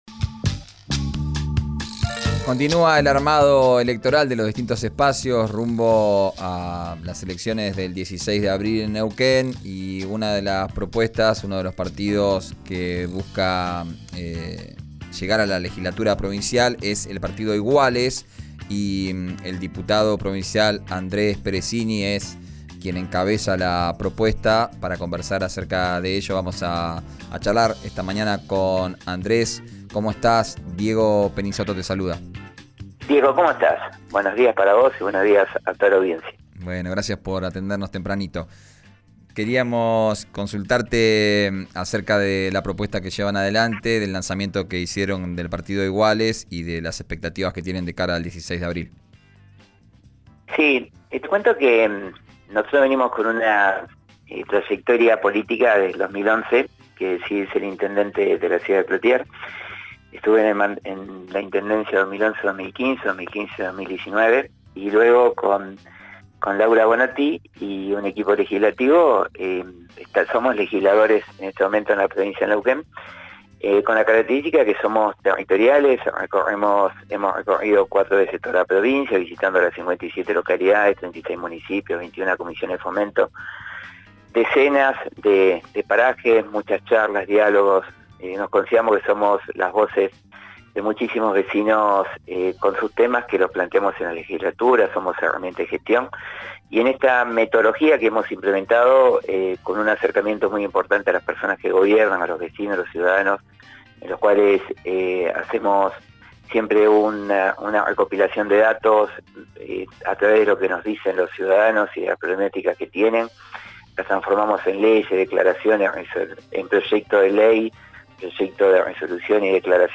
Escuchá la entrevista en 'Arranquemos', por RÍO NEGRO RADIO.